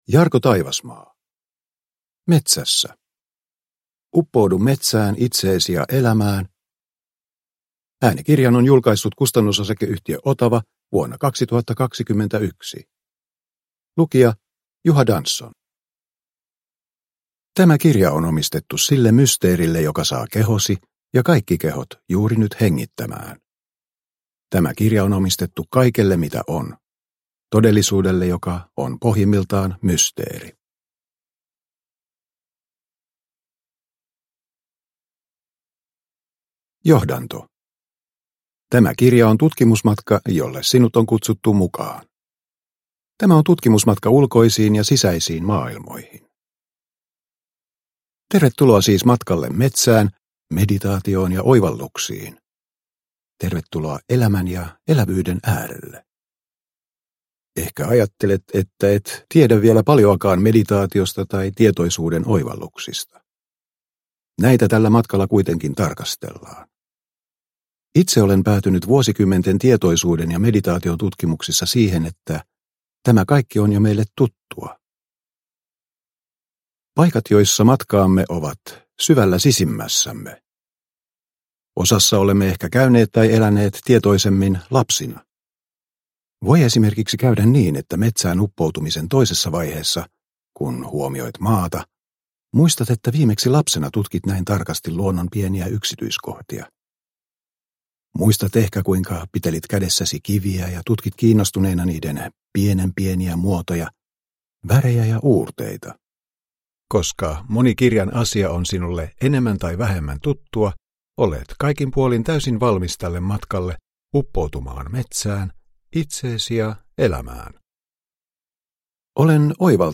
Metsässä – Ljudbok – Laddas ner